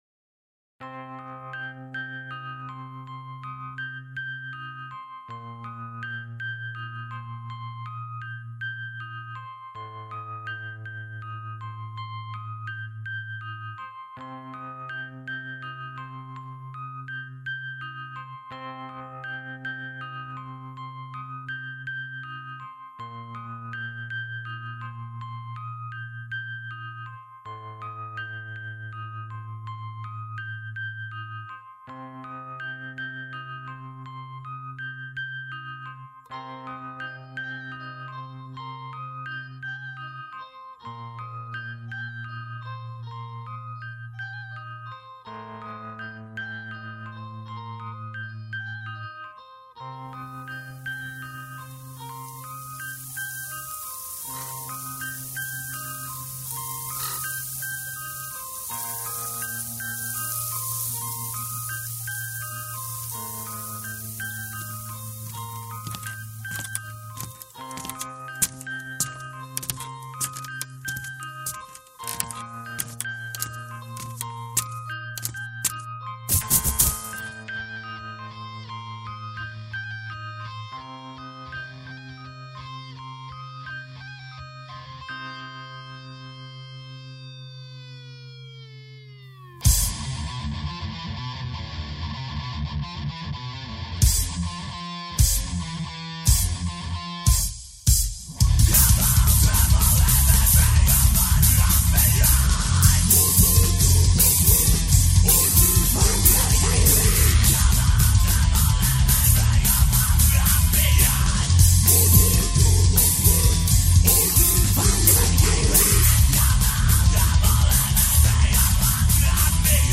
voce e chitarra